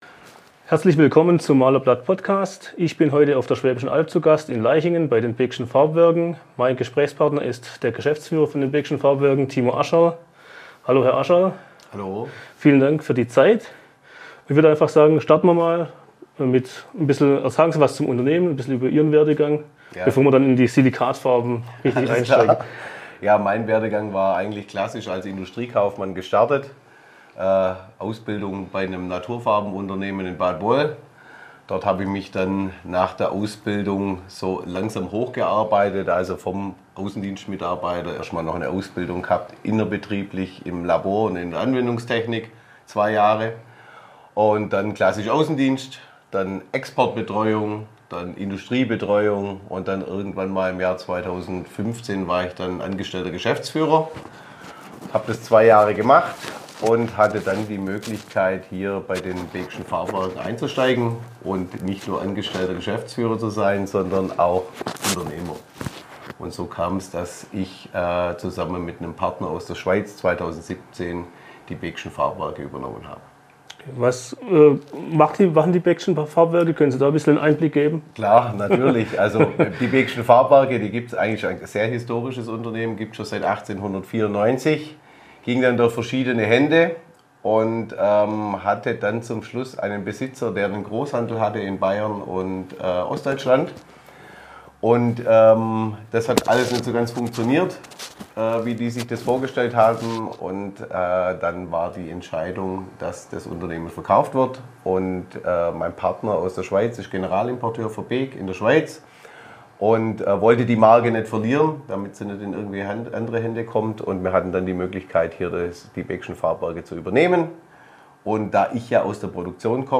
Schimmelschutz, Standzeit, Wirtschaftlichkeit – Silikatfarben erklärt ~ Der Malerblatt Podcast – Gespräche aus der Maler- und Lackiererbranche Podcast